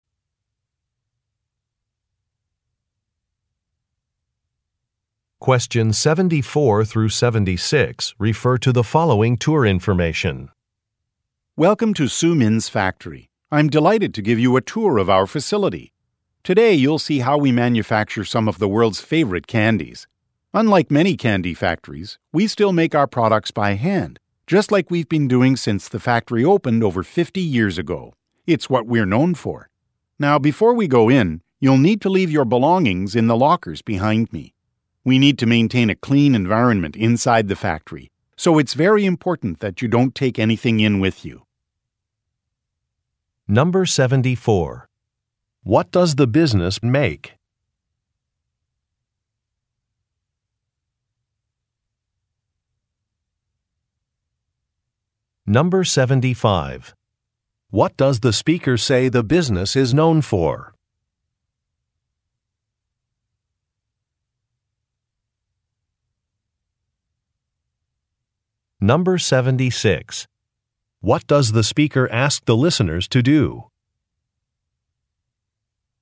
Question 74 - 76 refer to following talk: